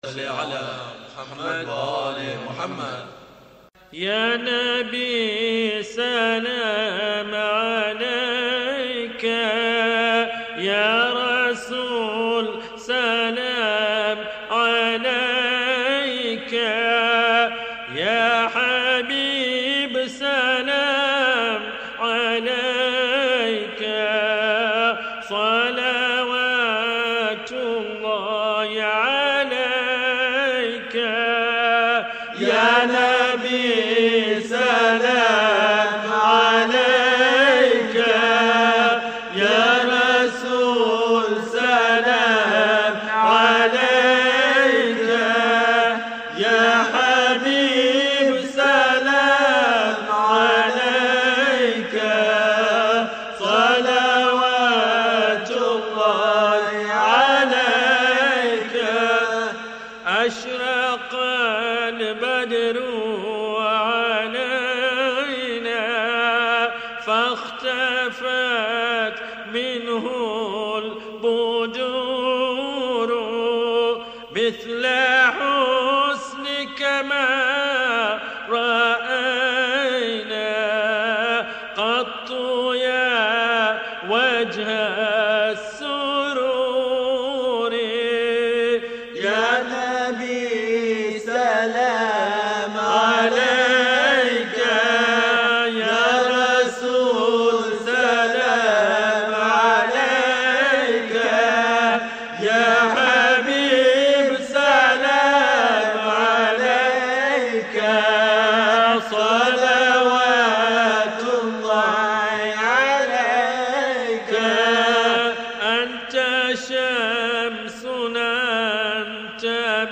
Non Saff Classic Munajaats